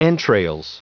Prononciation du mot entrails en anglais (fichier audio)
Prononciation du mot : entrails